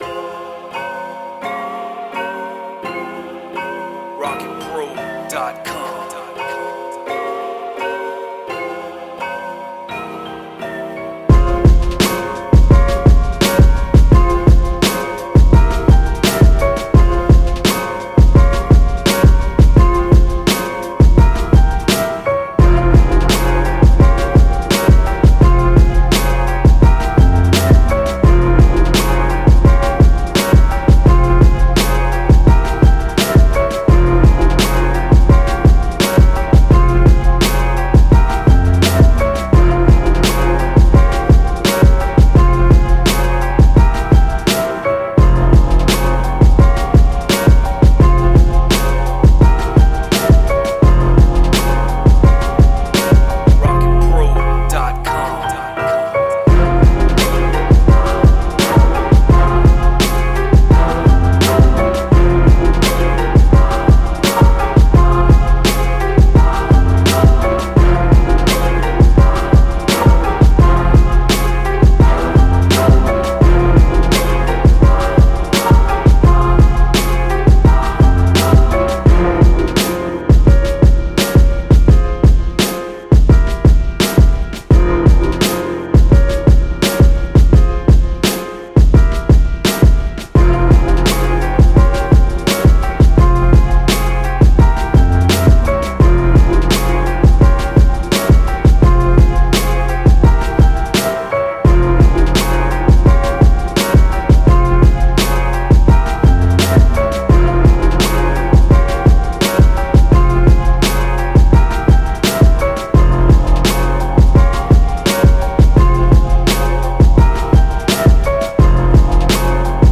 85 BPM.
Hip Hop beat with bell/piano chords and plucking strings.
Dark
East Coast